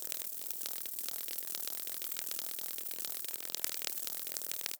InsectWingsFly_BU01.483.wav